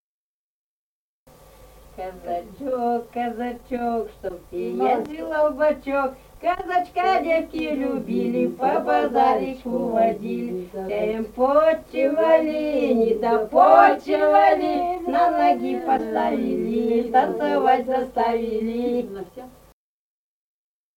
| filedescription = «Казачок, казачок», вечёрочная игровая.
Казахстанская обл., Катон-Карагайский р-н, с. Урыль (казаки), июль 1978.